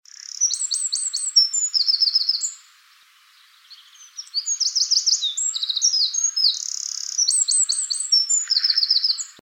Nome científico: Troglodytes troglodytes
Nome em Inglês: Eurasian Wren
Localidade ou área protegida: Milton Country Park
Condição: Selvagem
Certeza: Fotografado, Gravado Vocal
Eurasian-Wren.MP3